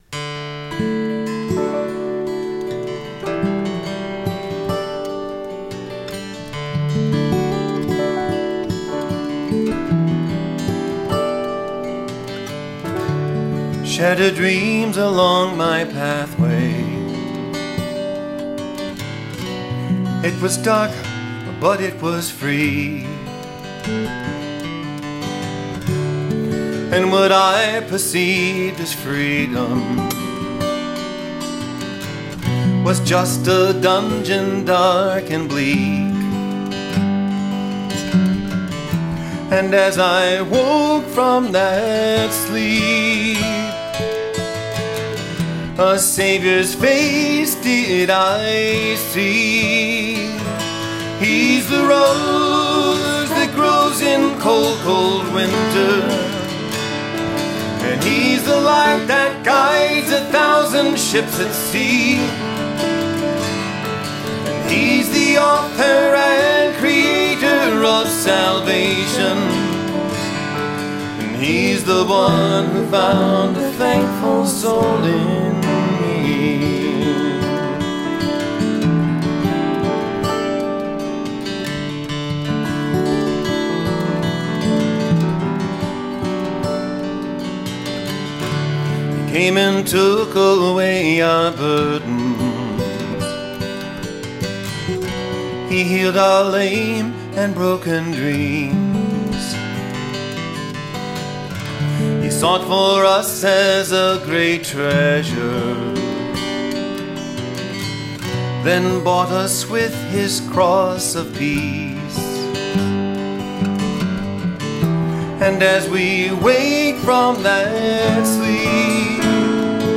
bass
vocals
guitar